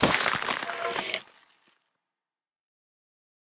Minecraft Version Minecraft Version snapshot Latest Release | Latest Snapshot snapshot / assets / minecraft / sounds / mob / stray / convert1.ogg Compare With Compare With Latest Release | Latest Snapshot